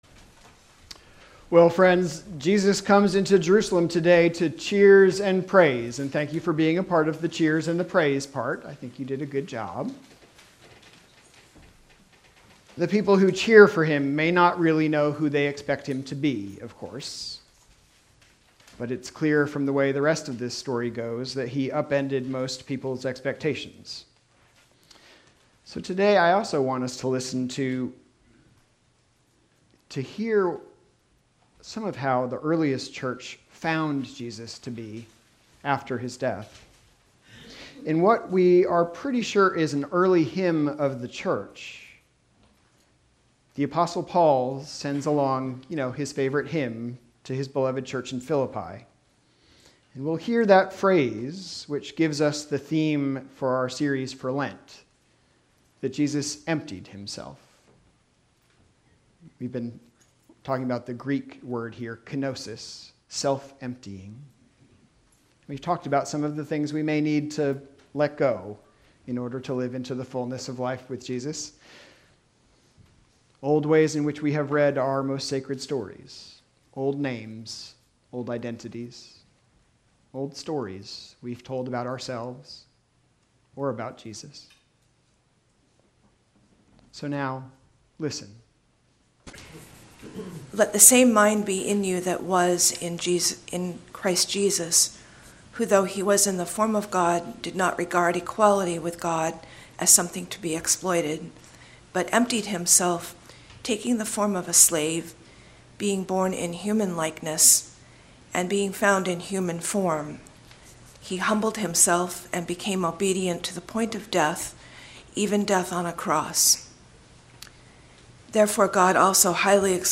Sermons, Services, and other events from Foreside Community Church in Falmouth, Maine